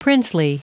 Prononciation du mot princely en anglais (fichier audio)
princely.wav